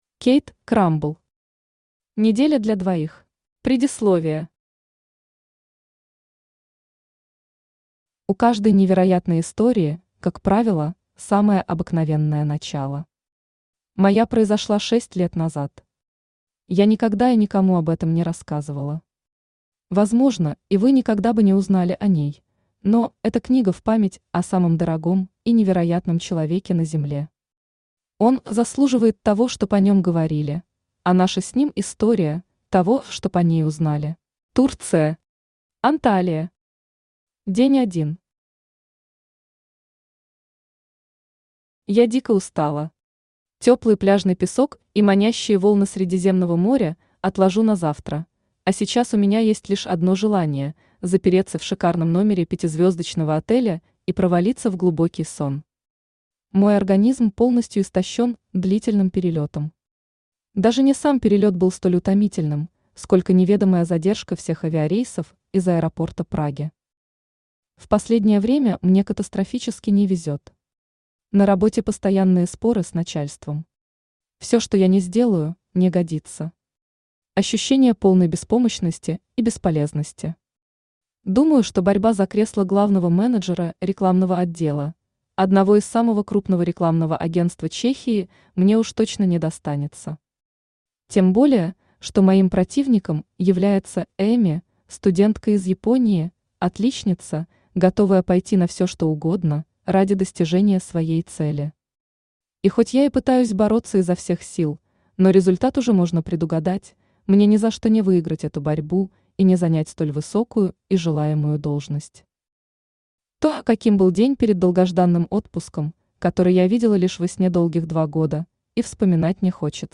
Аудиокнига Неделя для двоих | Библиотека аудиокниг
Aудиокнига Неделя для двоих Автор Кейт Крамбл Читает аудиокнигу Авточтец ЛитРес.